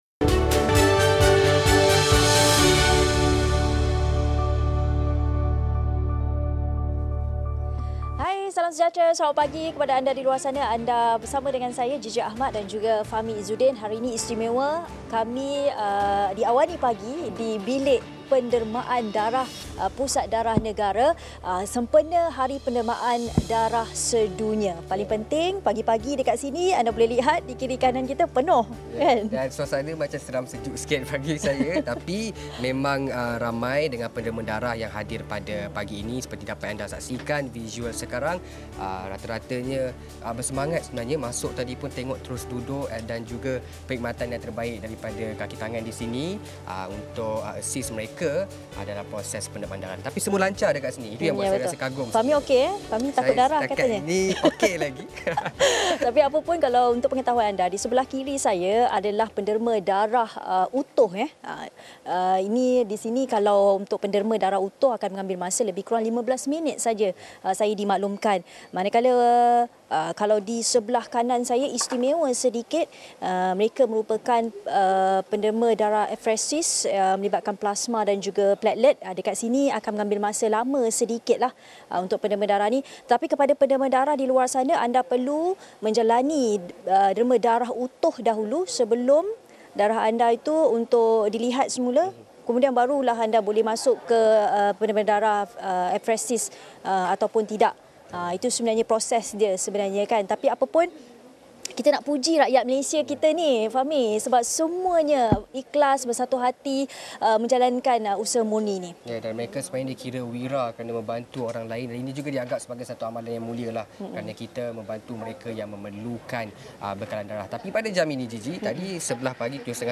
Headliner Embed Embed code See more options Share Facebook X Subscribe Apa kepentingan menderma darah dan sejauh mana budaya menderma darah di Malaysia? Bersiaran dari Pusat Darah Negara, Kuala Lumpur